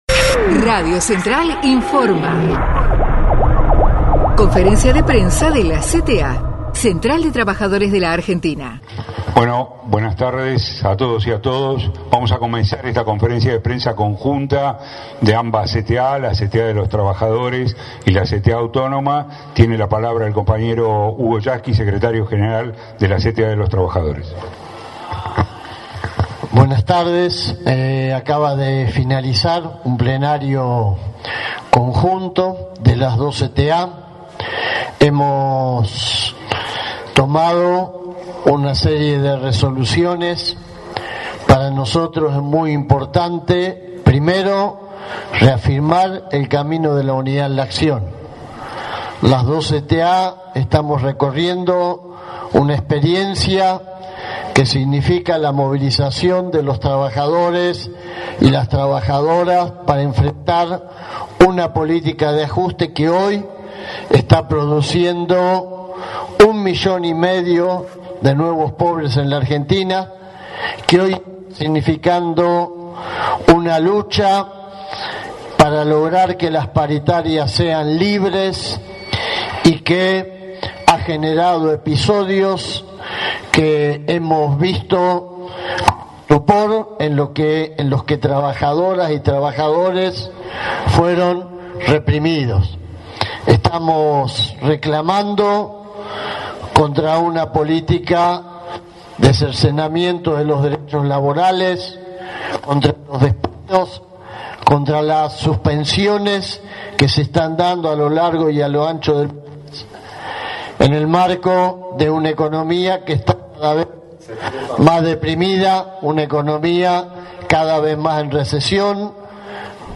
En conferencia de prensa, con la presencia de referentes y miembros de la conducción de ambas centrales a nivel nacional, Hugo Yasky y Pablo Micheli, secretarios generales de CTA de los Trabajadores y CTA Autónoma respectivamente, brindaron detalles de las próximas actividades.
CONFERENCIA DE PRENSA CTA: acto 1º de Mayo y segunda Marcha Federal
conferencia_de_prensa_yasky_y_micheli_26_4_07_final.mp3